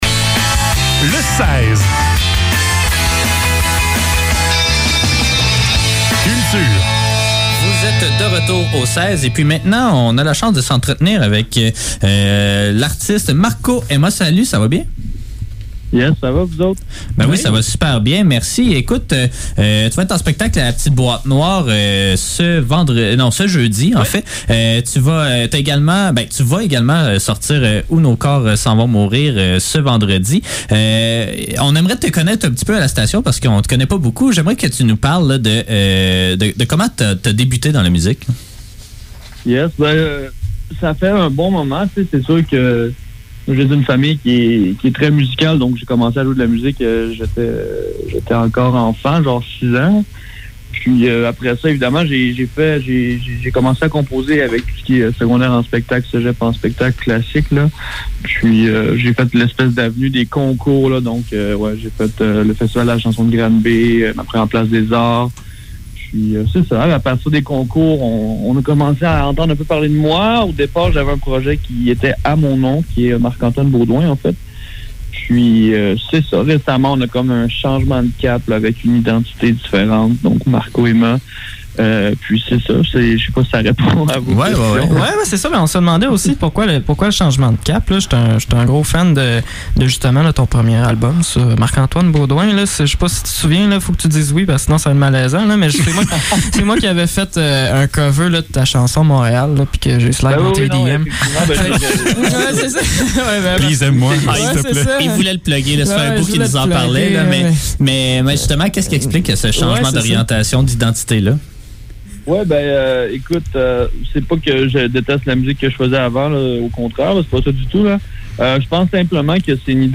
Les entrevues de CFAK Le seize - Entrevue